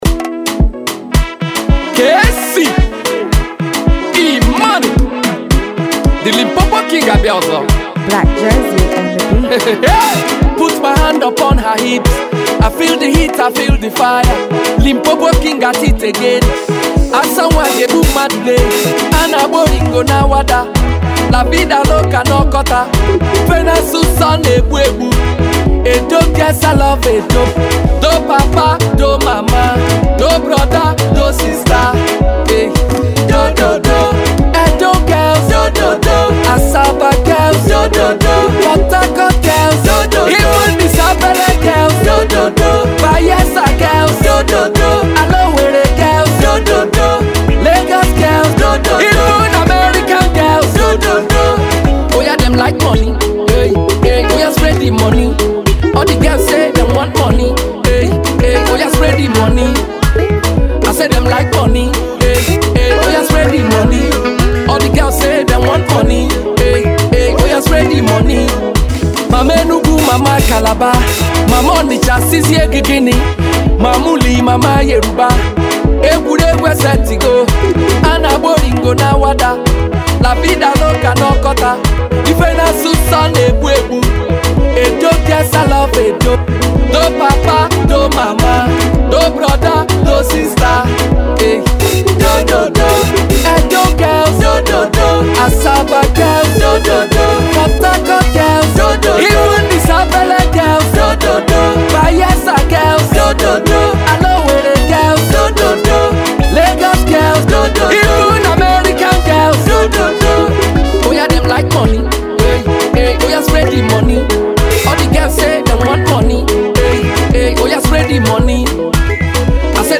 high-life song